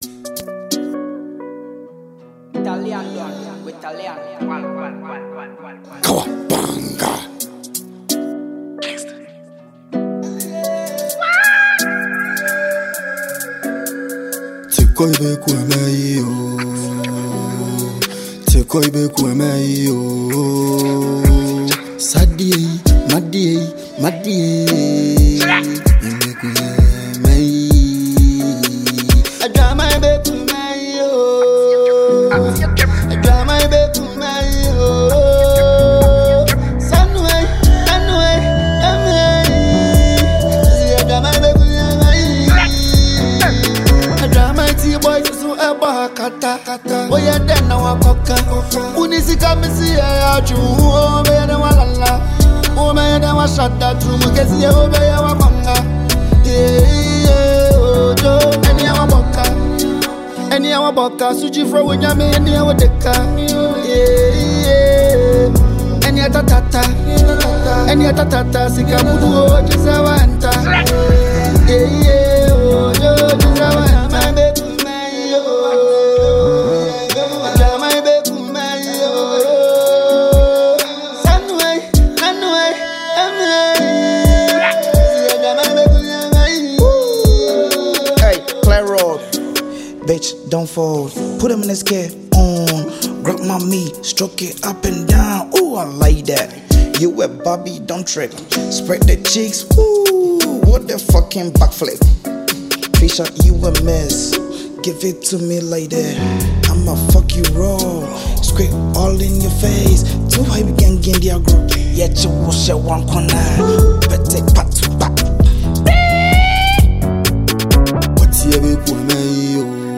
a new Ghanaian music track for fans and music lovers